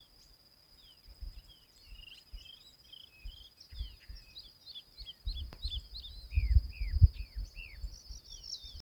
White-banded Mockingbird (Mimus triurus)
Country: Argentina
Location or protected area: San Salvador
Condition: Wild
Certainty: Observed, Recorded vocal